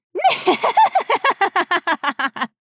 Evil Cackle Laugh 2
evil_cackle_laugh_2.ogg